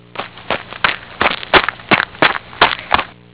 prancing and pawing of each little hoof.
hooves.wav